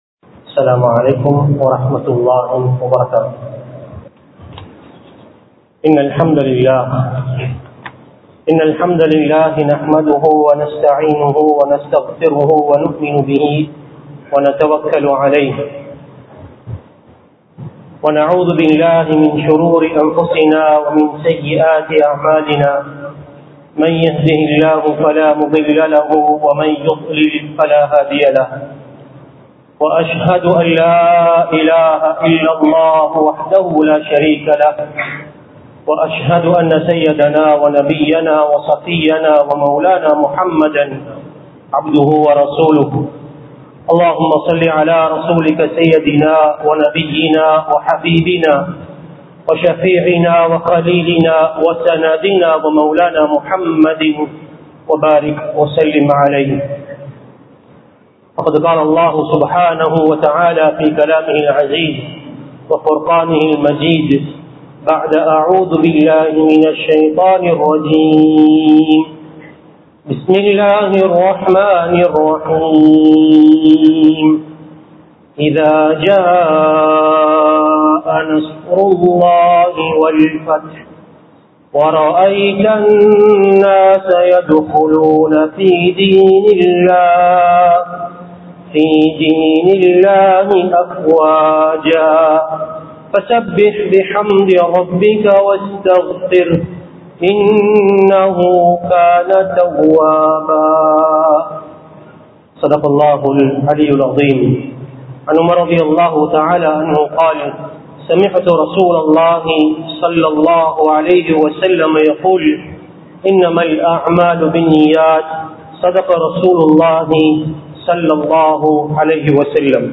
ஆரோக்கியம் | Audio Bayans | All Ceylon Muslim Youth Community | Addalaichenai
Colombo 12, Aluthkade, Muhiyadeen Jumua Masjidh 2026-04-10 Tamil Download